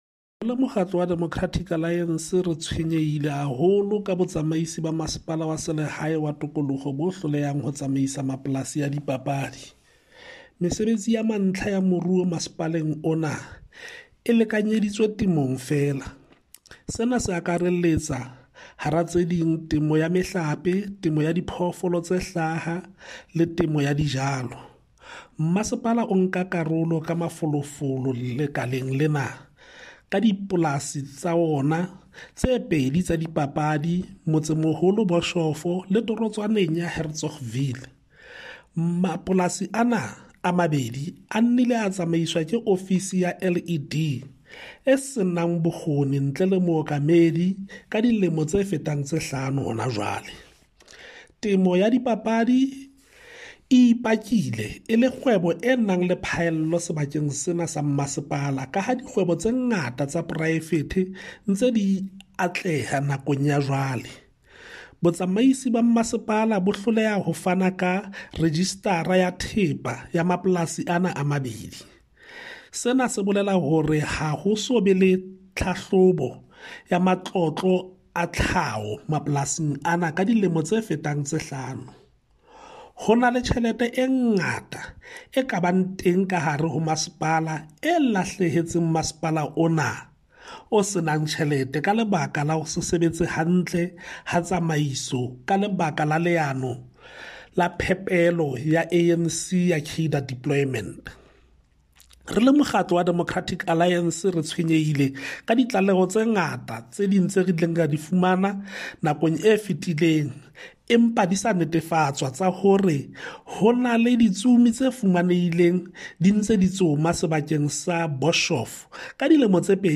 Sesotho soundbites by Cllr Hismajesty Maqhubu.
Sotho-voice-9.mp3